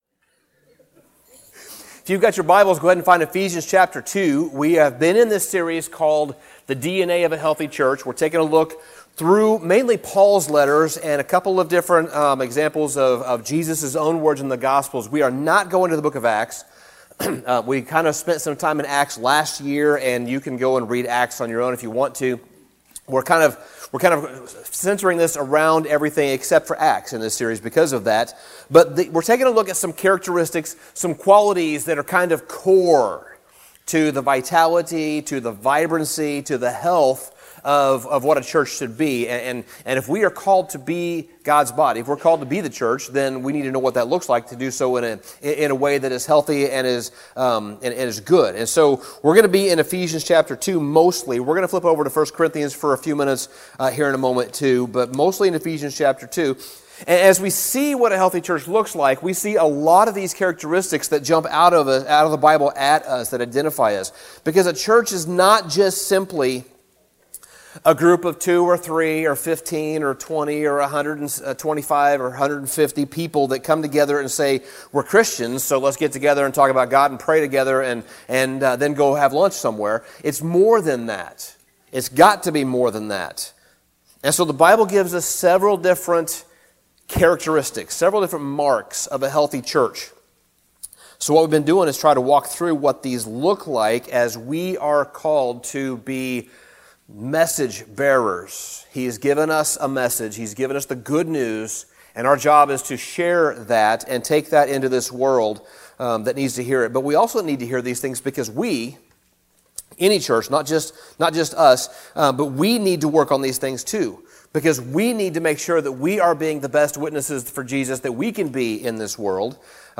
Sermon Summary Throughout the New Testament, Scripture uses several different analogies to describe the church.